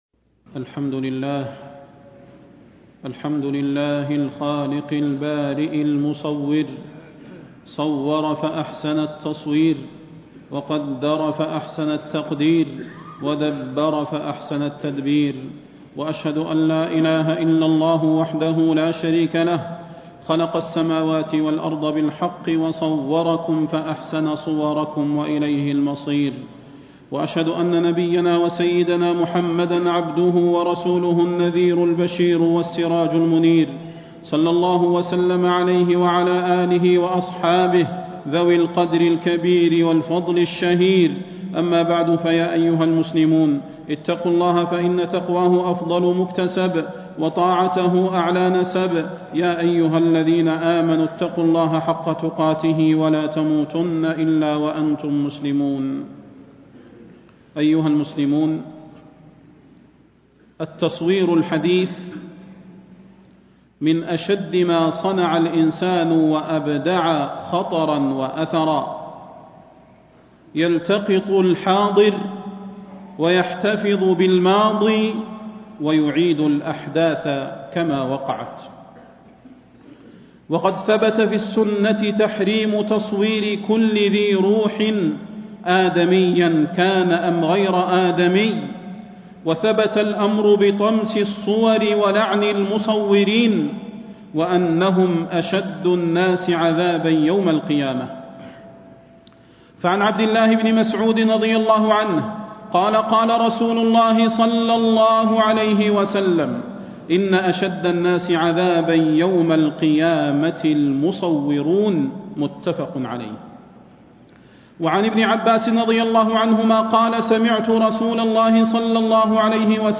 فضيلة الشيخ د. صلاح بن محمد البدير
تاريخ النشر ١١ جمادى الآخرة ١٤٣٥ هـ المكان: المسجد النبوي الشيخ: فضيلة الشيخ د. صلاح بن محمد البدير فضيلة الشيخ د. صلاح بن محمد البدير تحريم التصوير